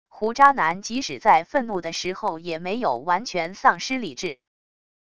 胡渣男即使在愤怒的时候也没有完全丧失理智wav音频